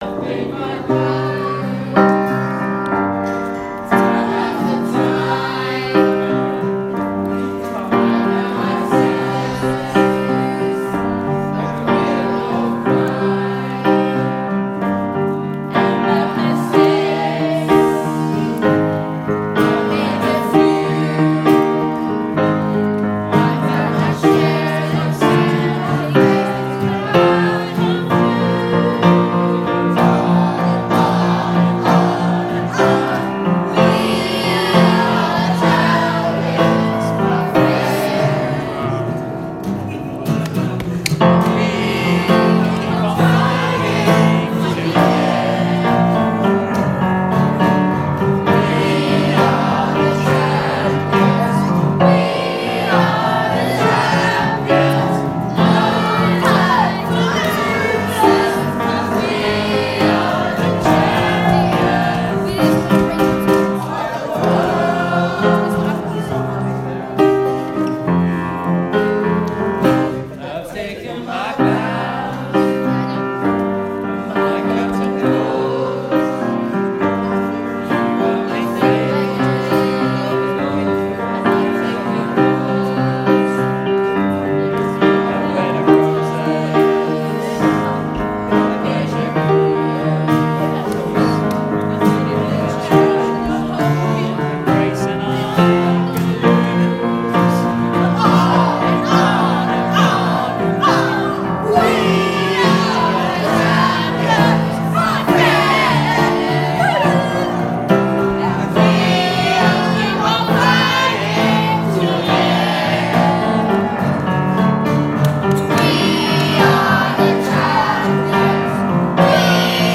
Lansdowne School Choir